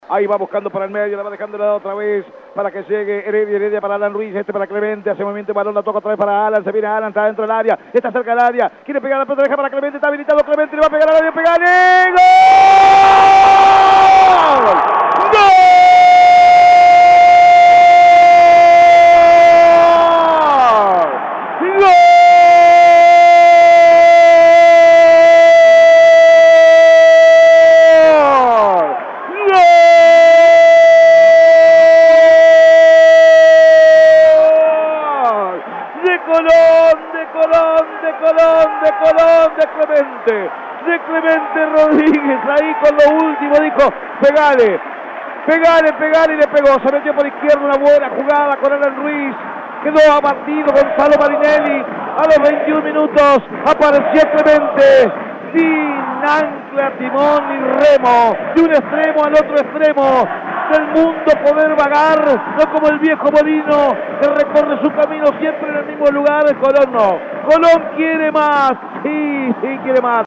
Reviví los goles de Colón con los relatos